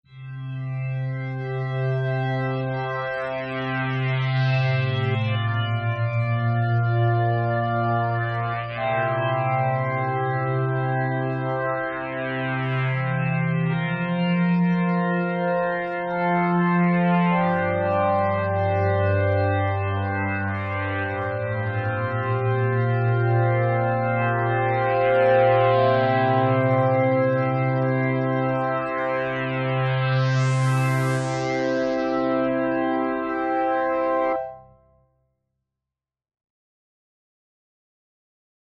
Quand au soit disant p600 qui envoit sévère je vous en rappelle le son :
Si tu prends la pire démo du P600, c'est sûr que ton argument tient la route, maintenant, je peux te dire que pour l'avoir testé en vrai, il peut envoyer de grosses nappes bien grasses qui dégomment !